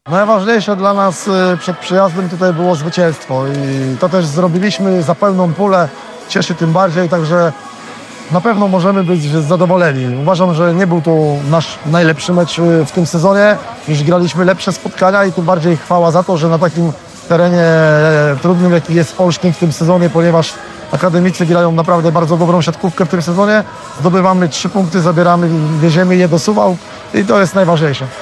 Pomeczowy komentarz